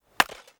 Chopping and Mining
chop 3.wav